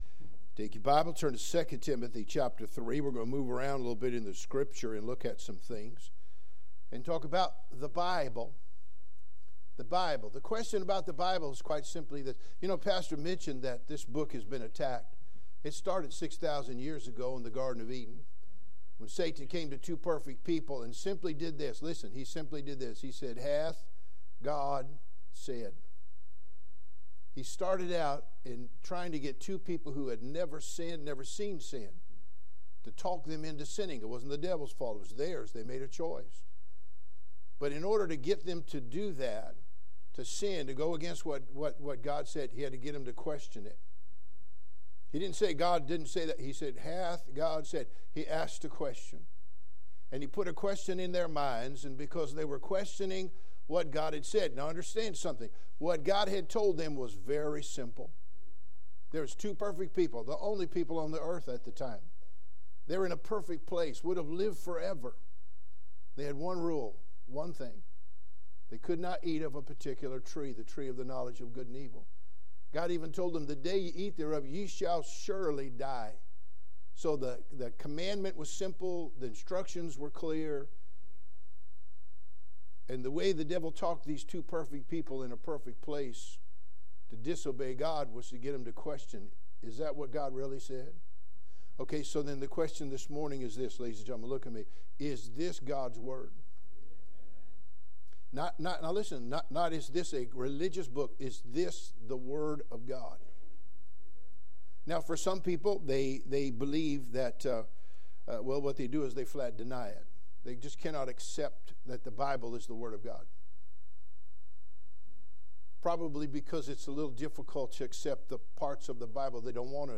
Bible | Sunday School